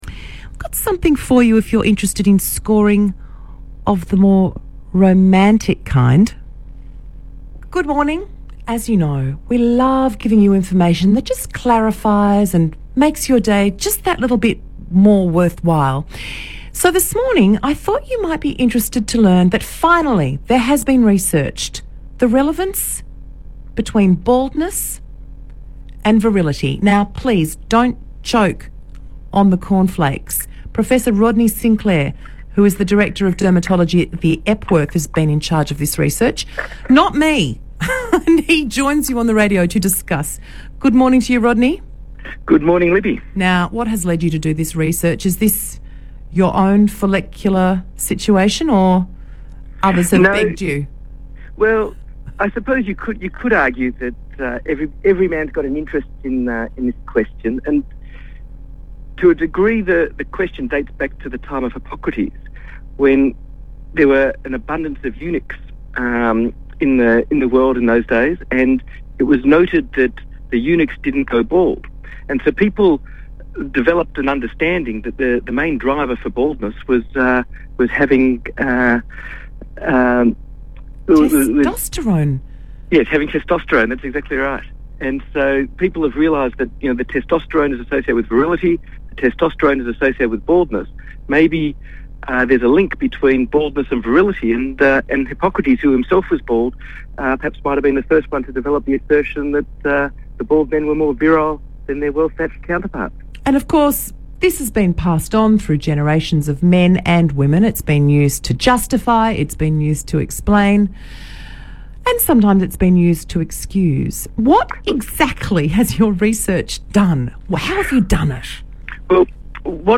ABC 774 interview